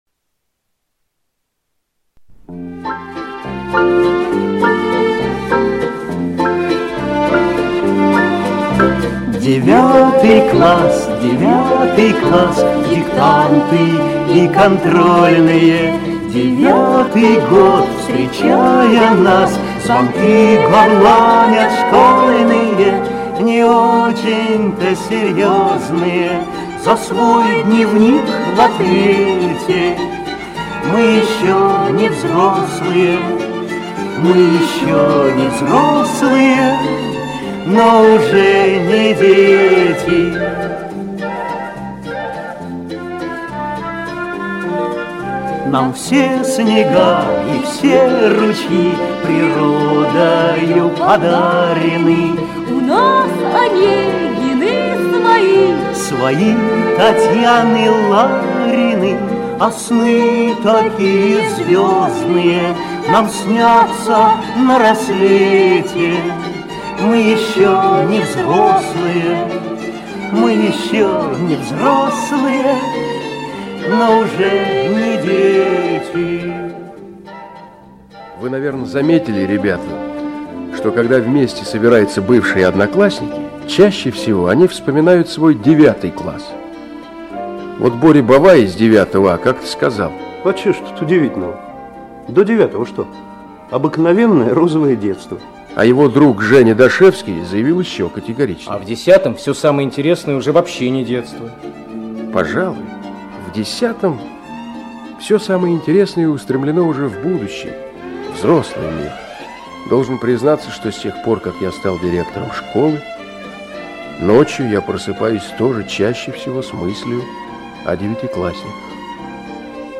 Девятый класс, девятый класс - аудио рассказ Черкашиной М. История о том, как молодая учительница Искра Сергеевна завоевала доверие ребят.